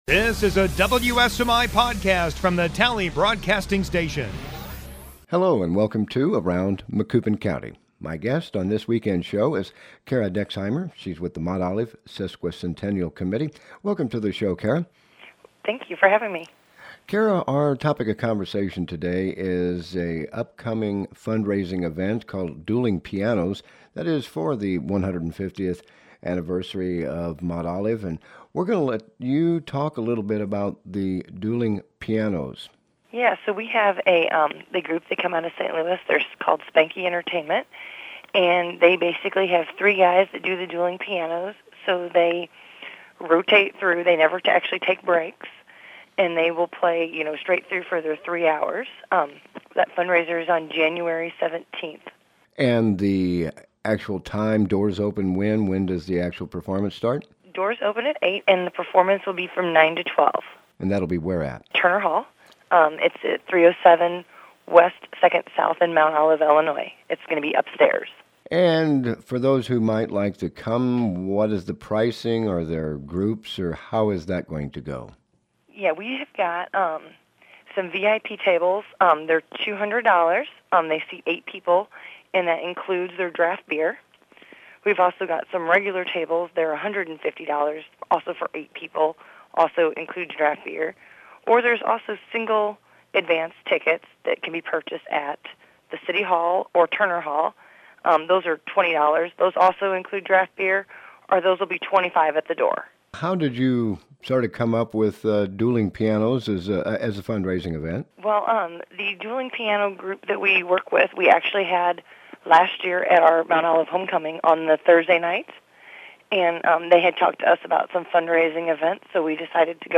Host: